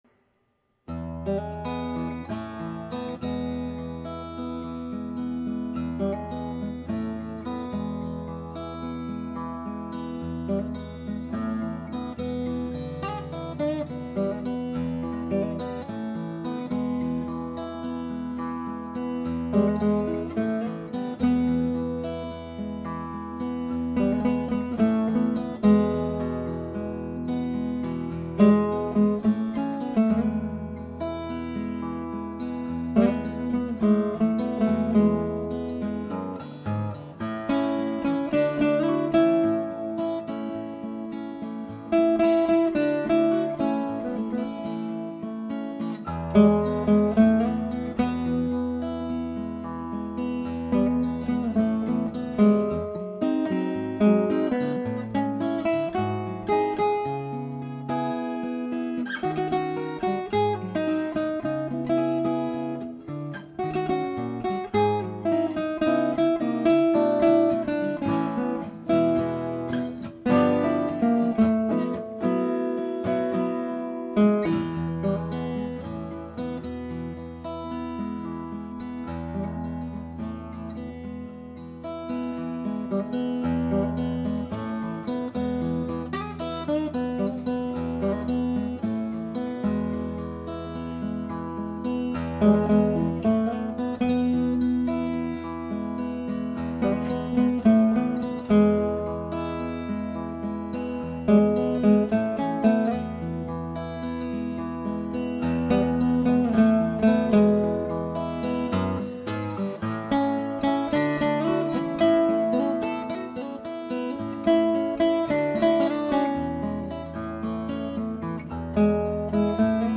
guitar
This husband and wife guitar duo have delighted audiences for over a decade gracing many fine occasions with their sophisticated stylings and extensive repertoire.